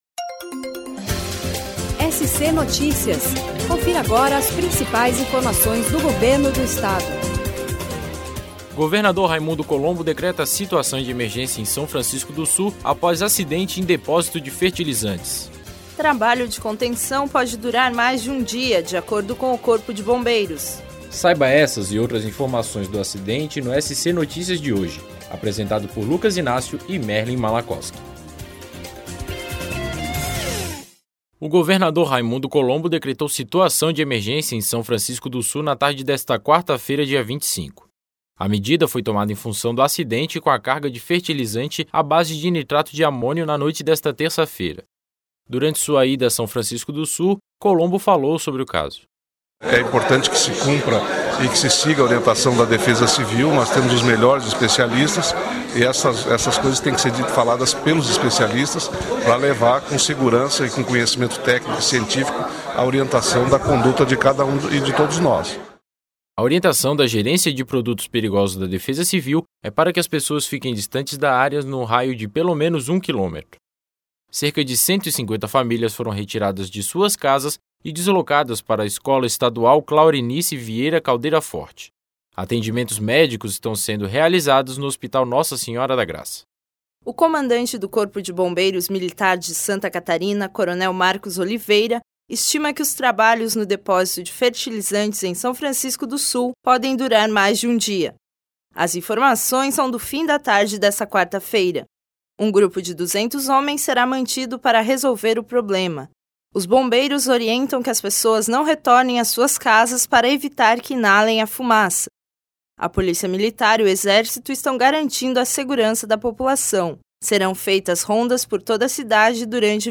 Boletim produzido pelo Serviço de Rádio da Secretaria de Estado da Comunicação com as principais informações do dia do Governo do Estado.